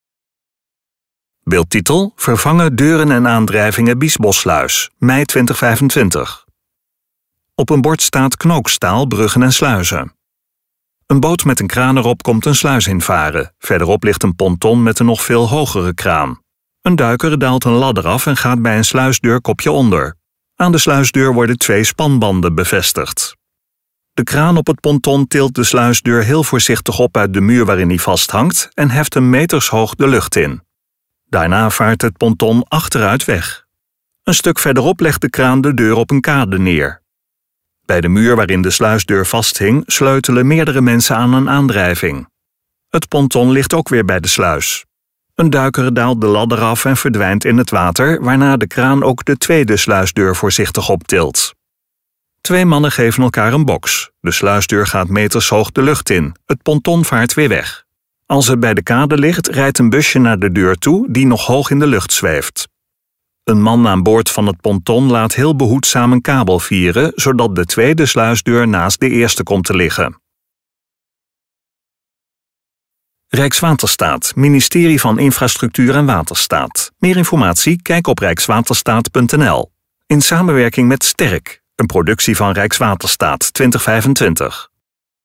RUSTIGE MUZIEK TOT HET EIND VAN DE VIDEO (De kraan op het ponton tilt de sluisdeur heel voorzichtig op uit de muur waarin hij vasthangt, en heft hem metershoog de lucht in.